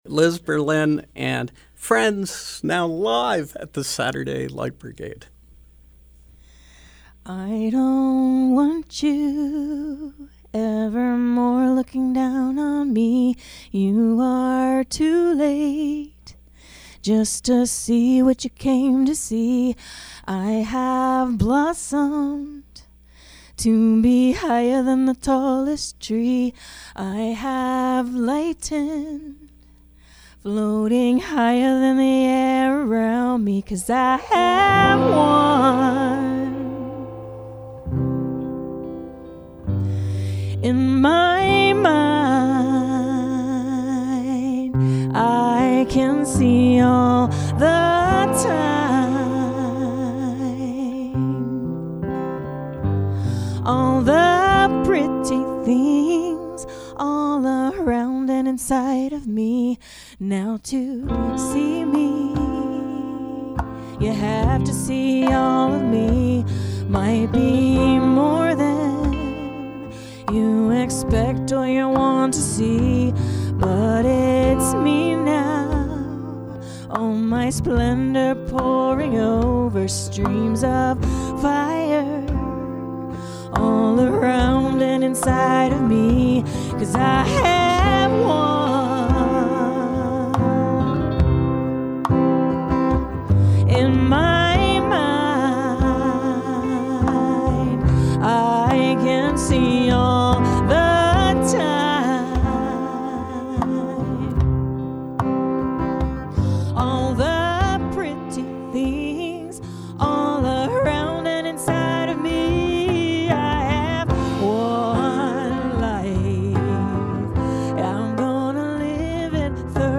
Music and conversation